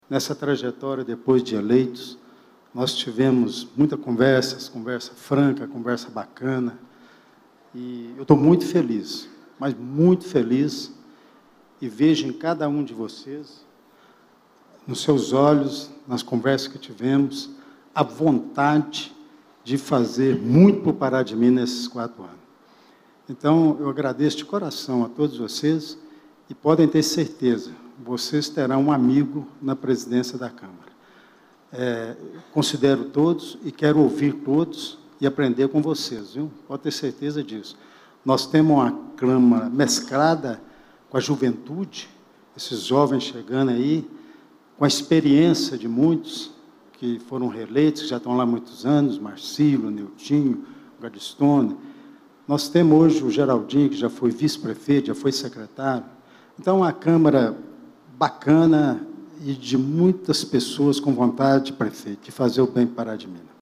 Os poderes Legislativo e o Executivo do Município Pará de Minas estão sob nova direção. Durante solenidade realizada no fim da tarde desta quarta-feira, 1º de janeiro de 2025, no Ápice Convenções e eventos, localizado no Bairro Providência, foram empossados vereadores para a legislatura 2025/2028, além do prefeito e vice-prefeito.